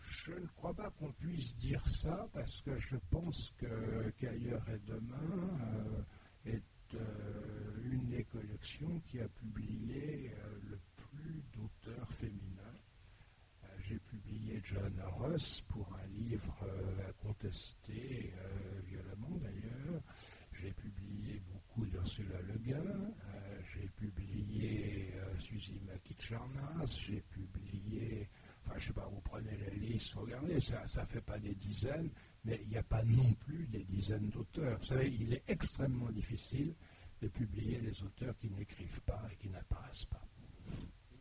Voici l'enregistrement de la conférence avec Gérard Klein aux rencontres de l'Imaginaire de Sèvres du 12 décembre 2009 à l'occasion des 40 ans d'Ailleurs et demain.
Les questions du public: